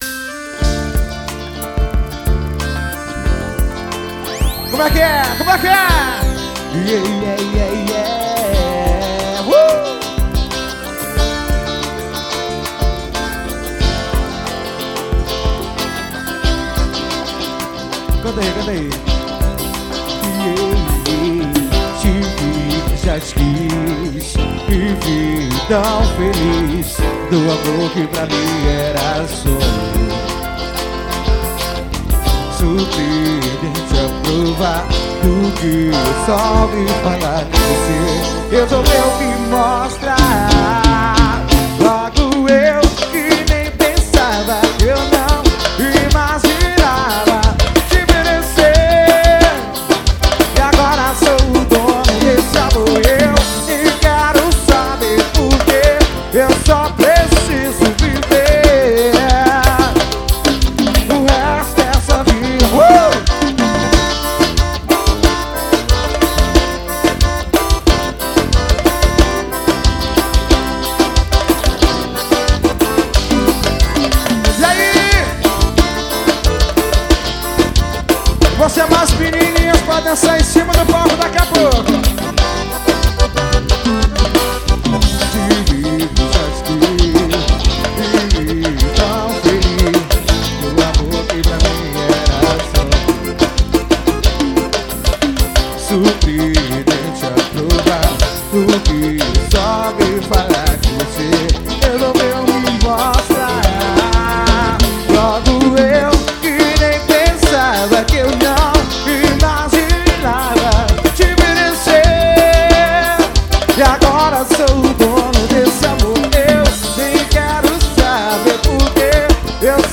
no esquenta da festa da independência